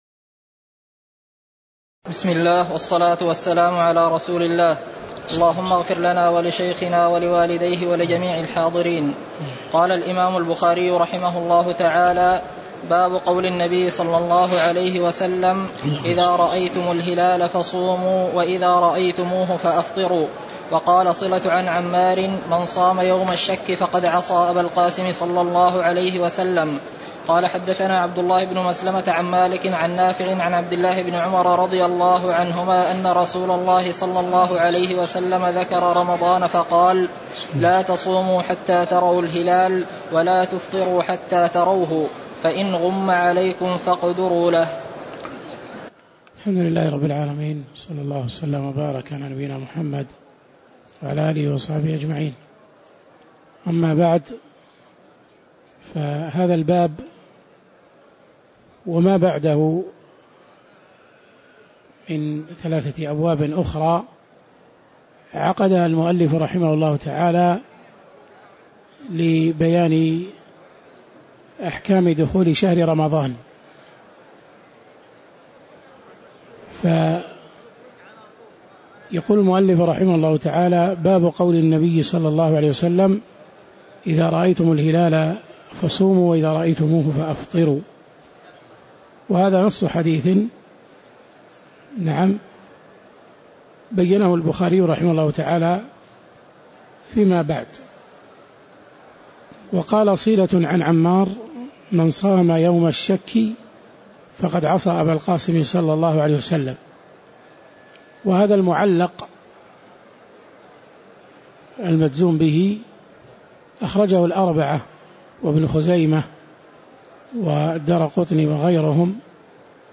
تاريخ النشر ٤ رمضان ١٤٣٨ هـ المكان: المسجد النبوي الشيخ